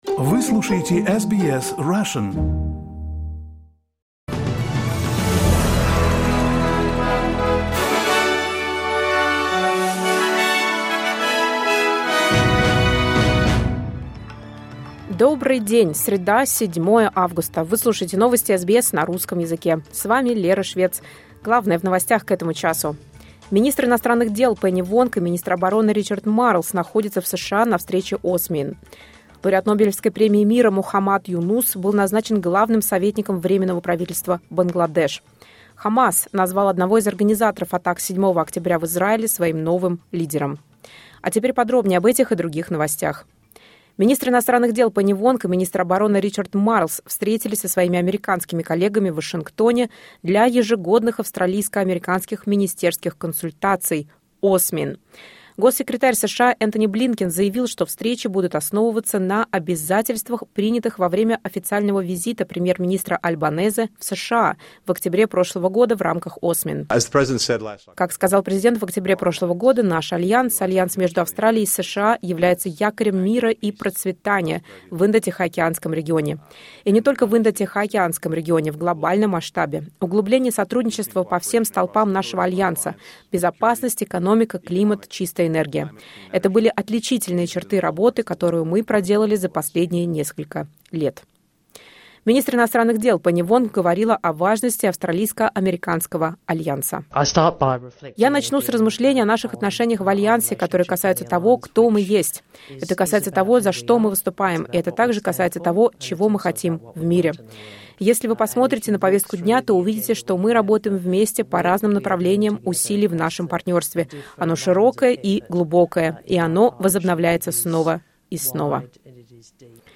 Новости SBS на русском языке — 07.08.2024